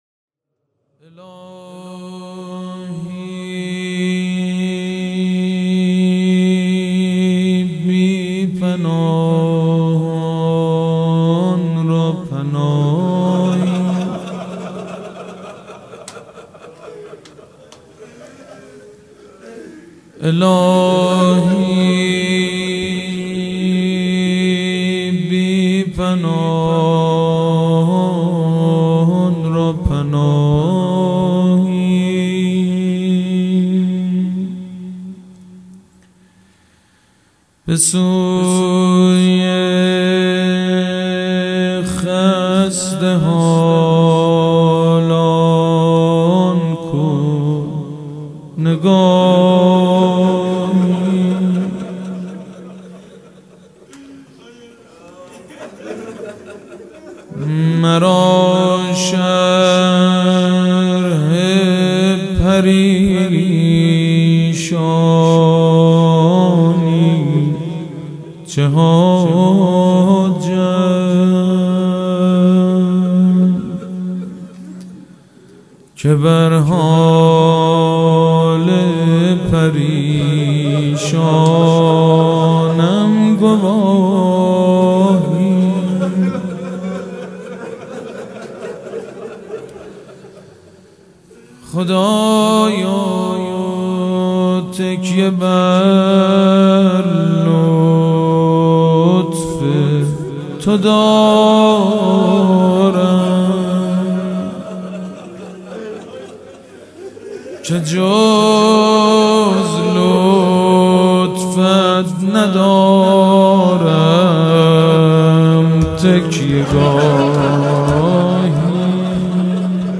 مراسم مناجات شب بیستم ماه رمضان
مناجات
مداح